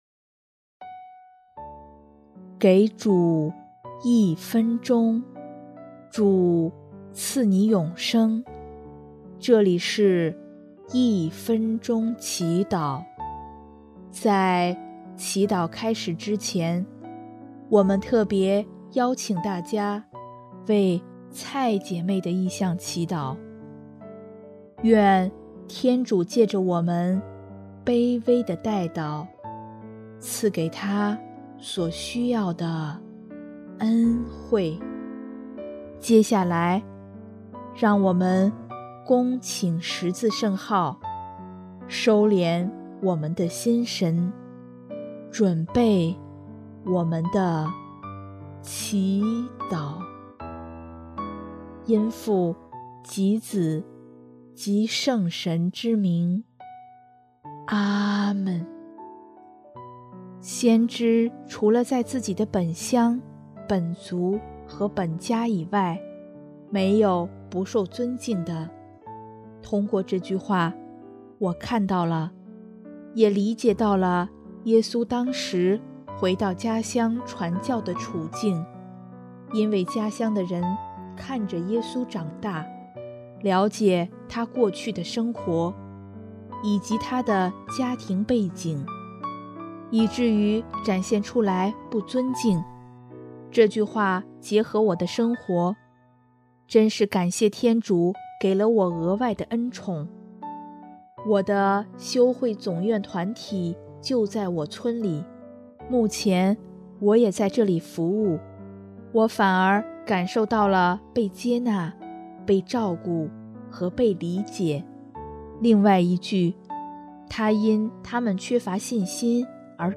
音乐：第四届华语圣歌大赛参赛歌曲《重返乐园》